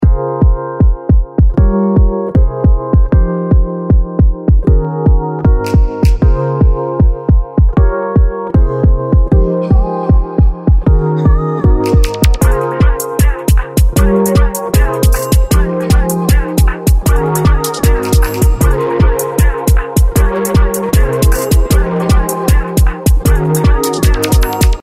CHORUS系2段がけにSATURATION、WAHWAHに空間系というチェーンですが、ワイド感と透明感に若干の歪みが加わり、まさにDisclosureの音、といった感じになっています。
曲は前回と同じトラックを使用していますが、曲全体ではなくRhodesのパートだけにかけています。